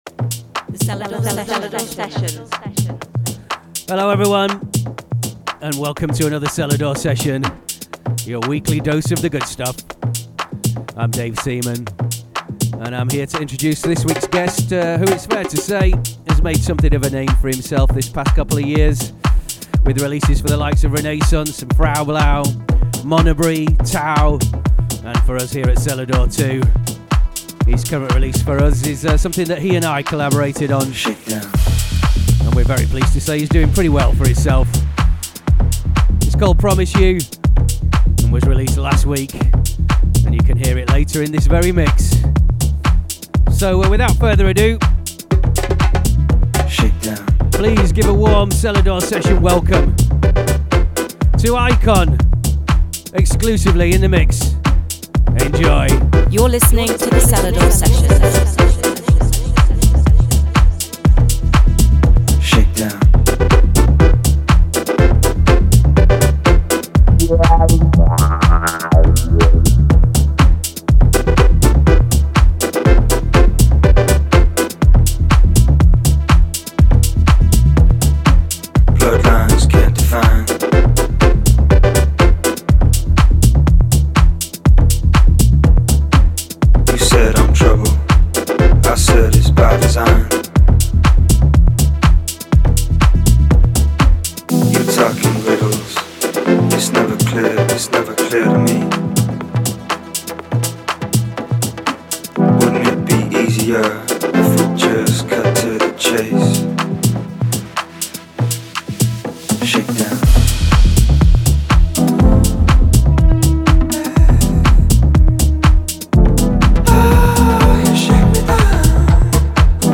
Exclusively in the mix.